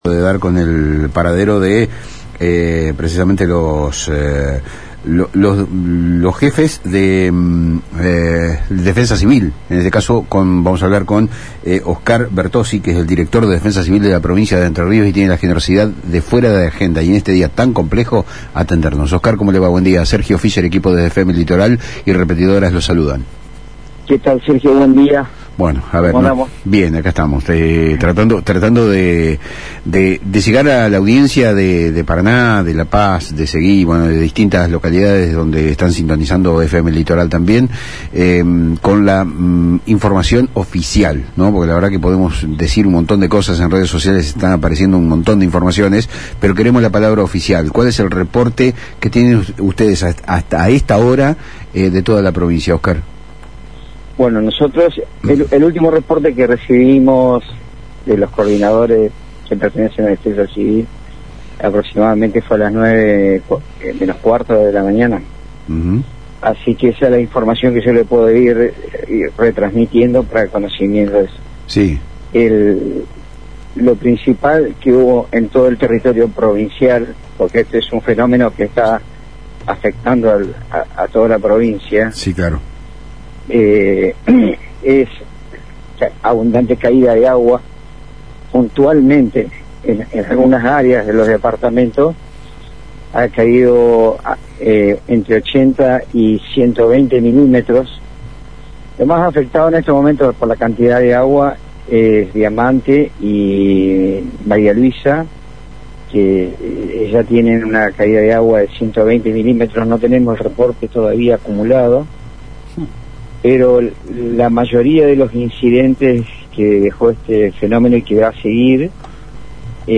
En diálogo con FM Litoral, Oscar Bertozzi brindó el primer reporte oficial de daños y acciones de rescate.